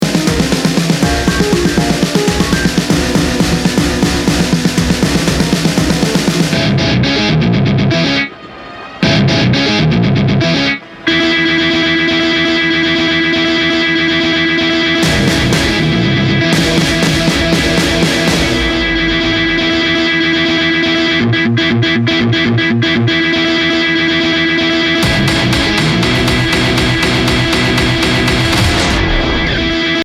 electric guitars by Ibanez and Schecter